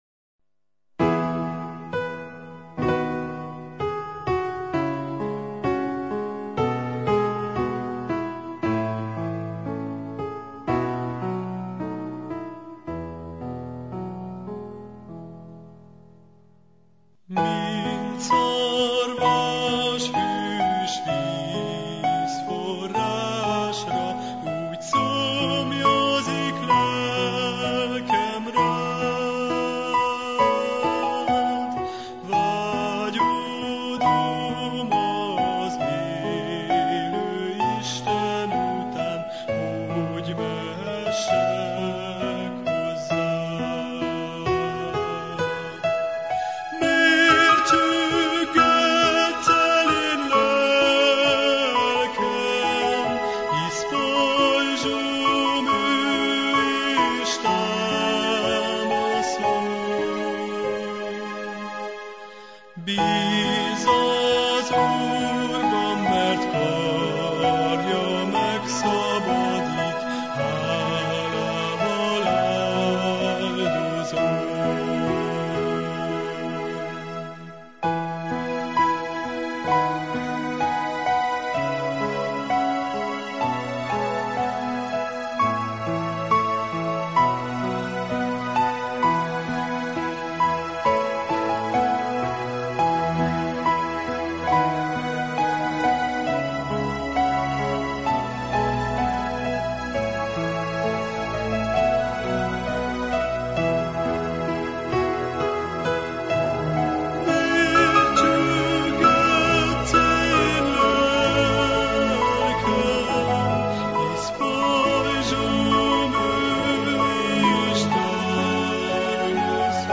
Református felekezet 2016. július 31-i adása – Bocskai Rádió